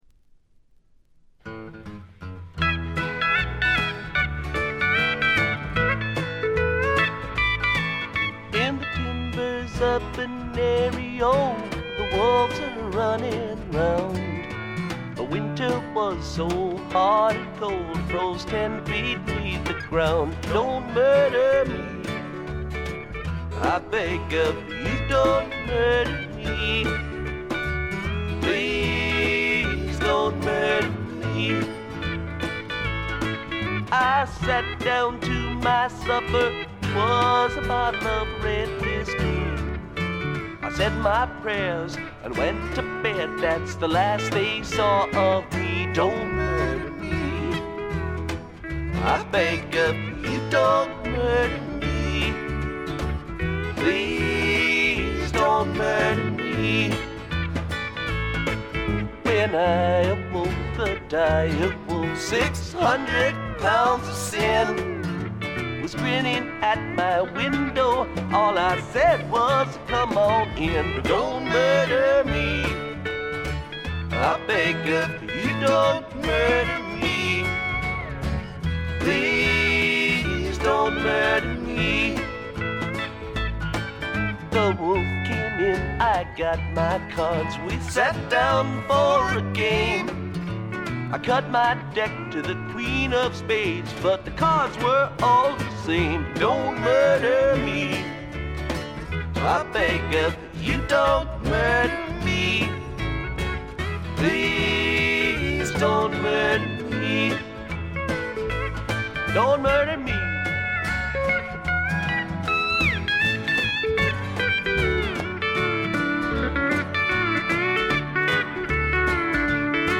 部分試聴ですがほとんどノイズ感無し。
試聴曲は現品からの取り込み音源です。